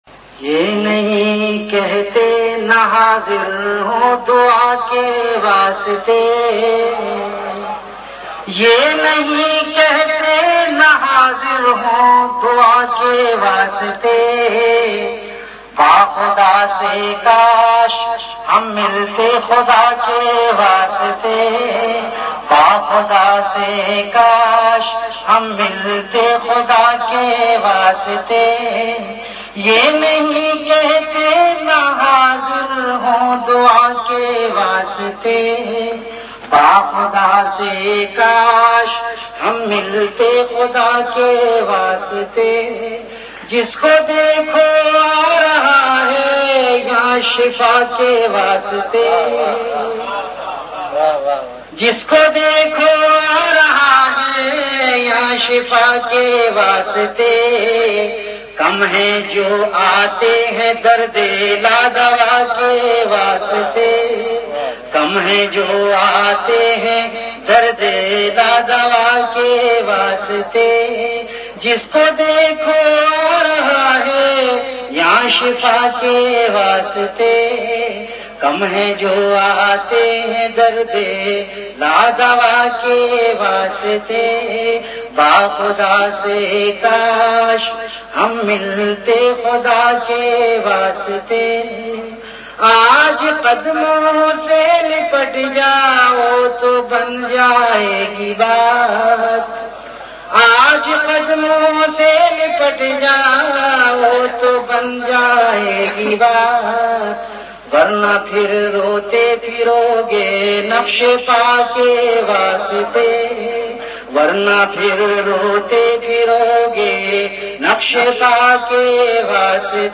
Ashaar · Khanqah Imdadia Ashrafia
VenueKhanqah Imdadia Ashrafia
Event / TimeAfter Isha Prayer